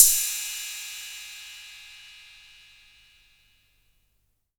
• Cymbal D Key 10.wav
Royality free cymbal sound sample tuned to the D note. Loudest frequency: 8417Hz
cymbal-d-key-10-3hj.wav